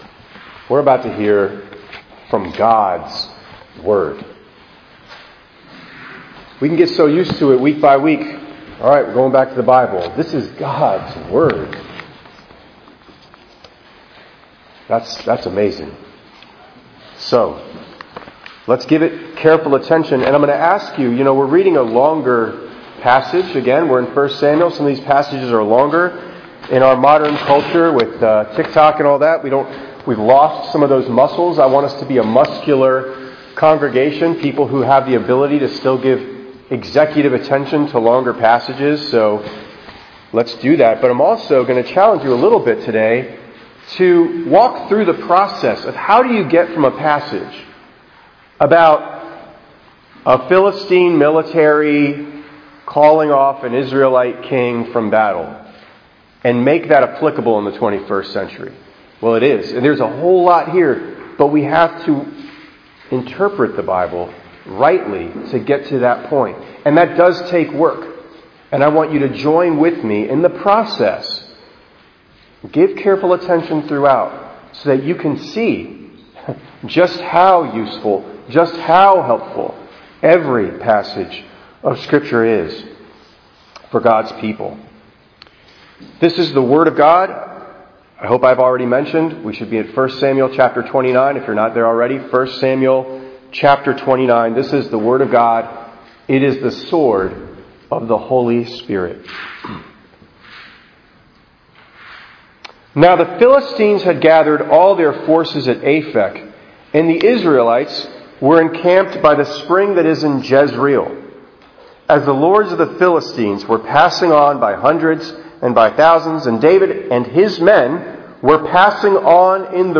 4_12_26_ENG_Sermon.mp3